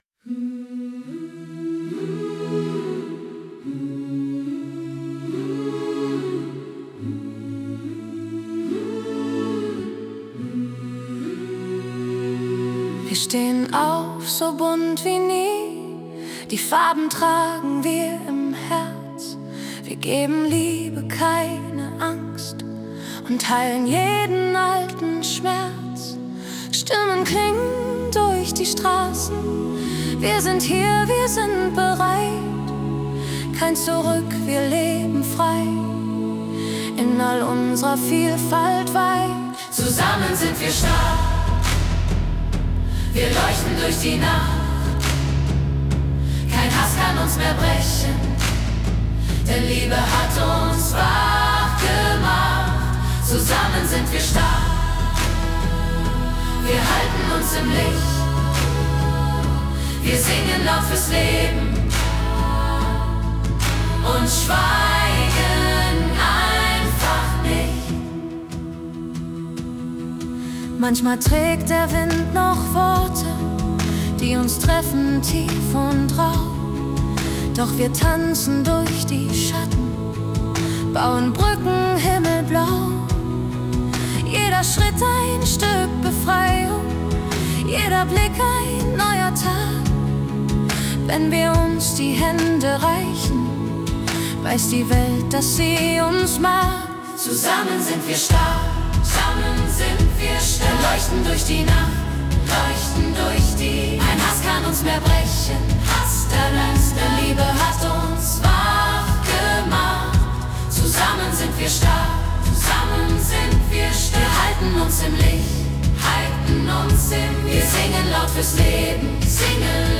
Der Song ist als Chorstück konzipiert, weil ich die Kraft vieler Stimmen nutzen wollte, um ein Zeichen gegen Hass und Diskriminierung zu setzen. Musikalisch und textlich trägt das Lied eine positive, kämpferische Energie – es soll Hoffnung wecken und Menschen verbinden.